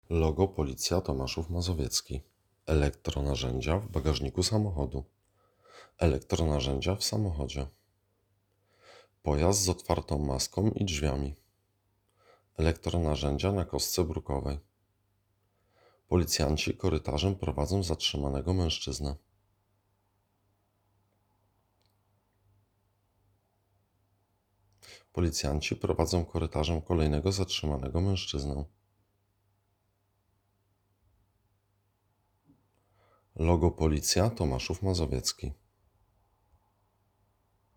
Nagranie audio Audiodeskrypcja_do_filmu.m4a